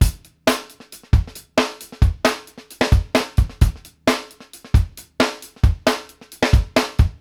FUNK 101  -R.wav